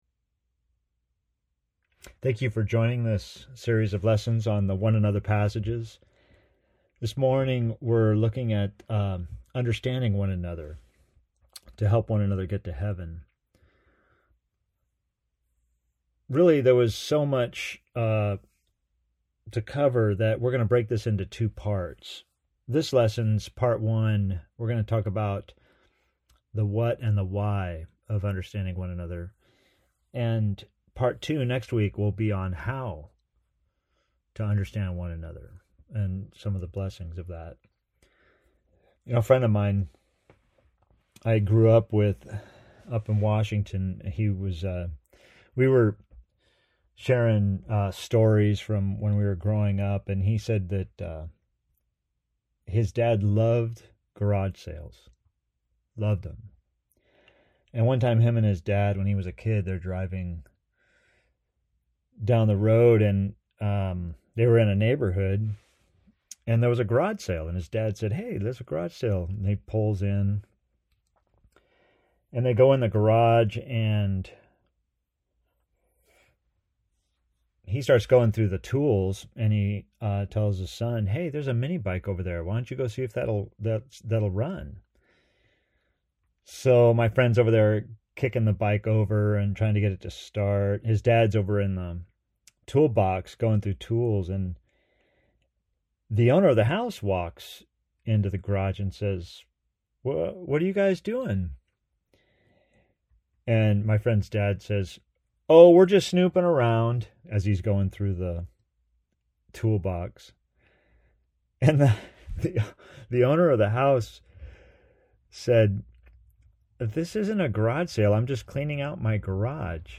Sermon pre-recorded for Sunday 11/29/20 AUDIO | TEXT PDF Understanding One Another Share this: Share on X (Opens in new window) X Share on Facebook (Opens in new window) Facebook Like Loading...